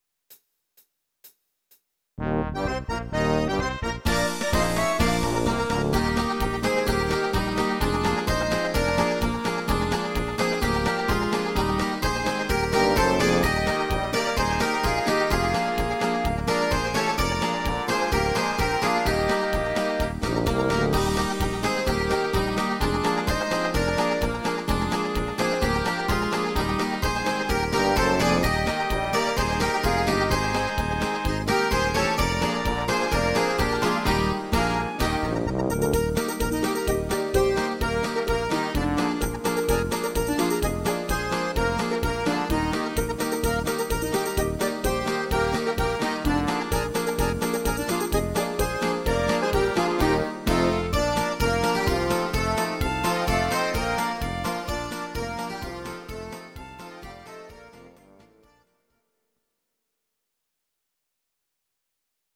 instr. Orchester